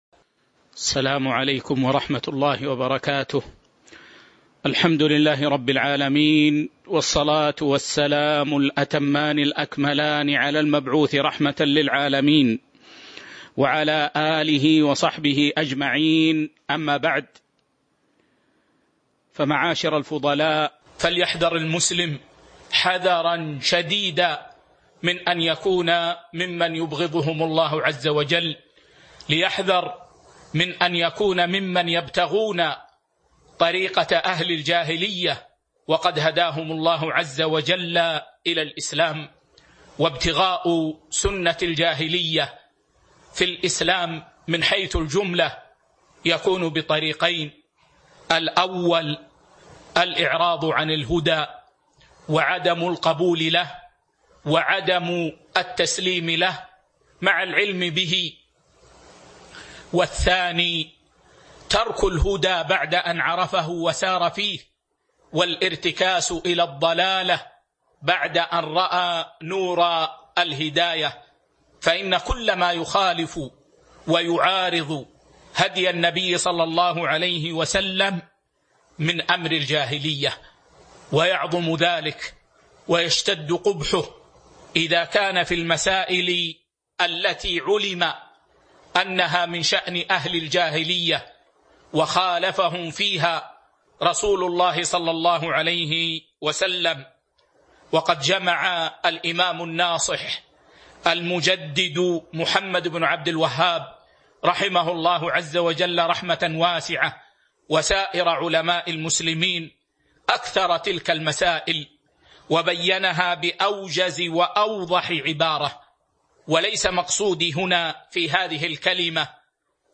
تاريخ النشر ٧ صفر ١٤٤٢ هـ المكان: المسجد النبوي الشيخ